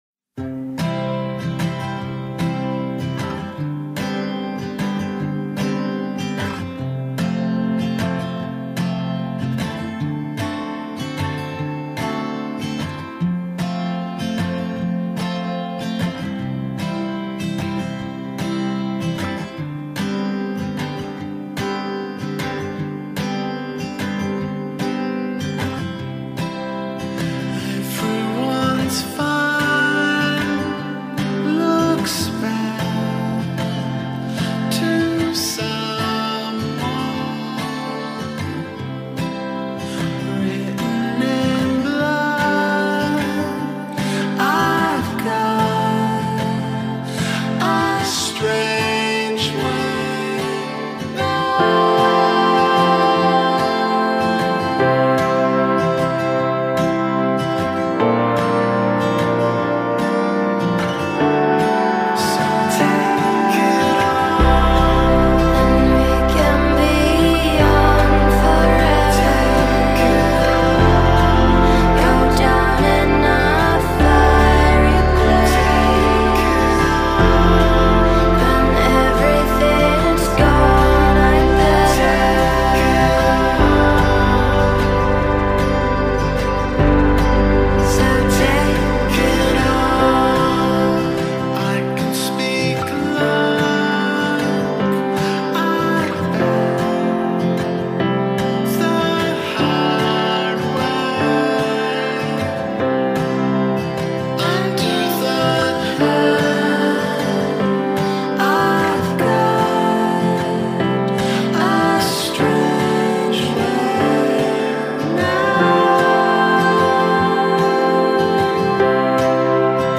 an electronic influenced pop artist out of Los Angeles.